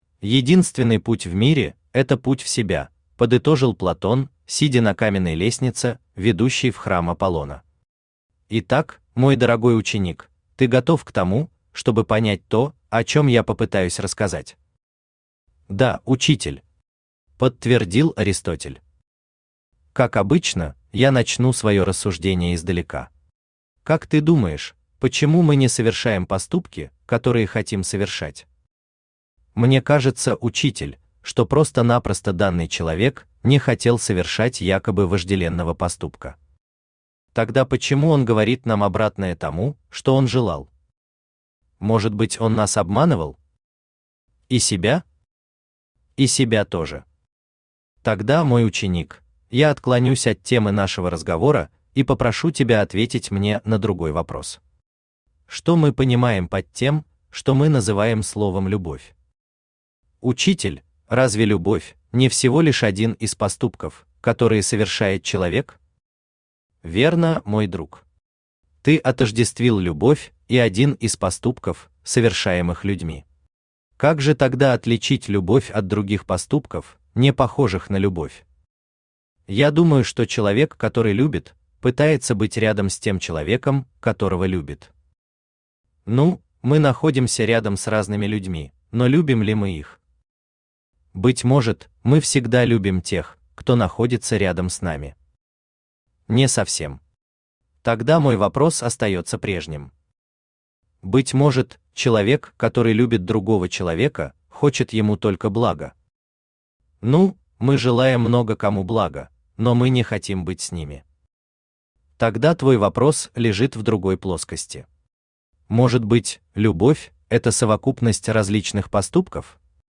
Аудиокнига Посиделки у Платона | Библиотека аудиокниг
Aудиокнига Посиделки у Платона Автор Виталий Александрович Кириллов Читает аудиокнигу Авточтец ЛитРес.